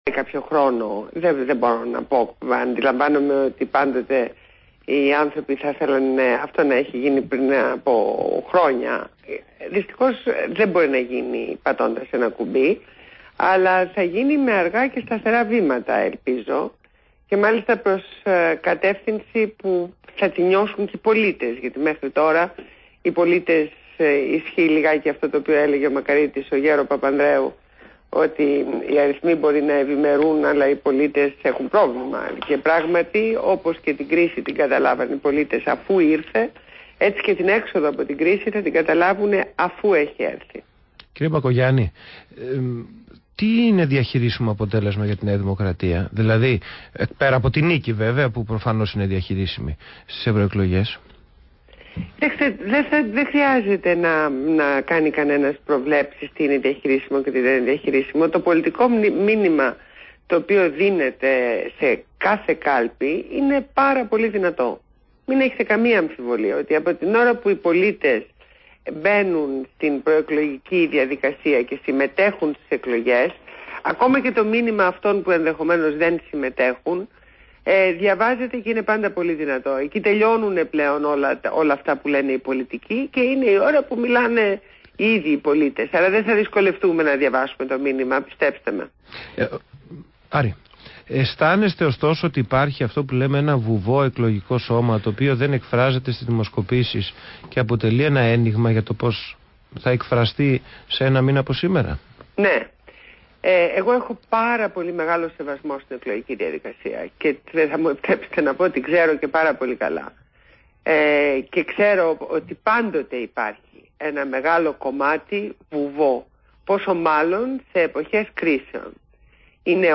Συνέντευξη στο ραδιόφωνο ΒΗΜΑfm